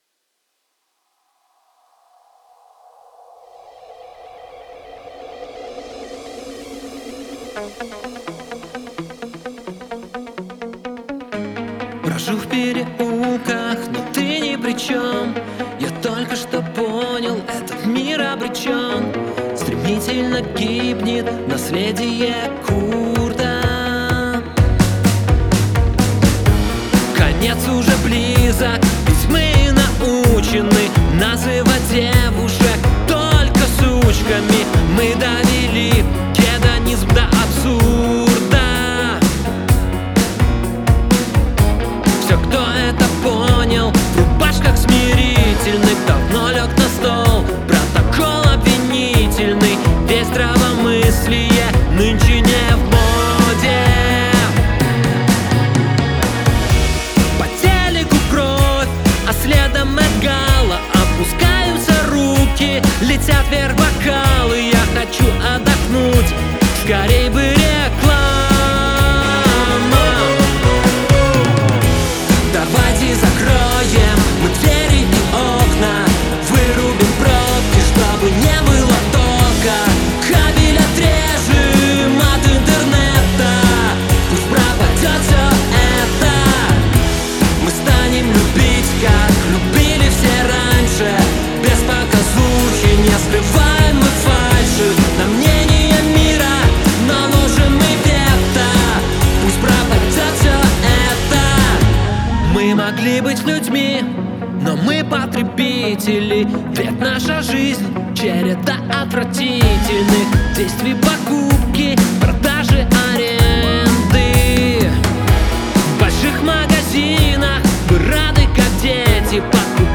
[PRE-RELEASE] alter.rock,pop,old-new wave